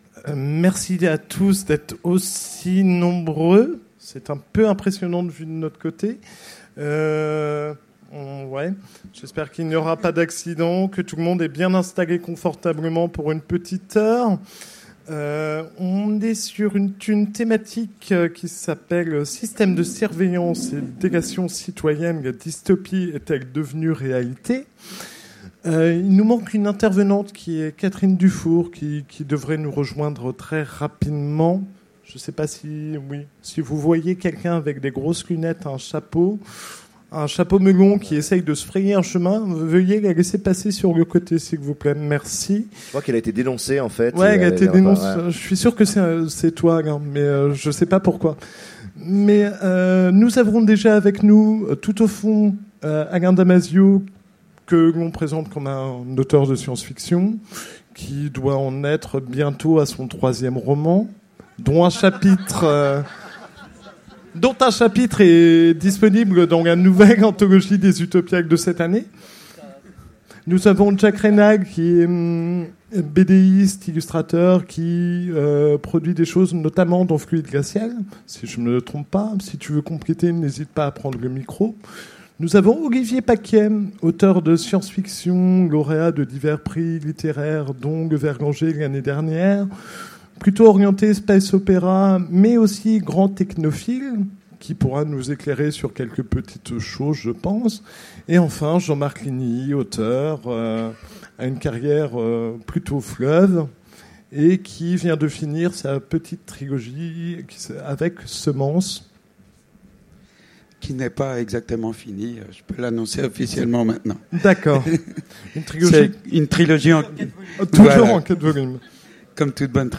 Utopiales 2015 : Conférence Systèmes de surveillance et délation citoyenne